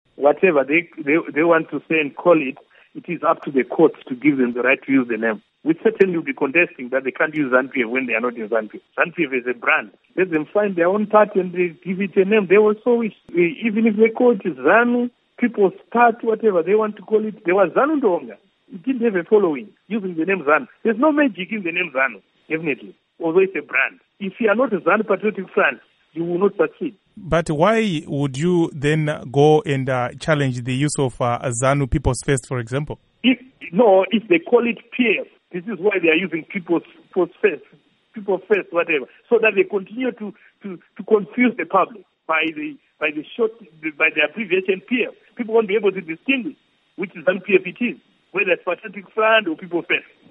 Interview With Paul Mangwana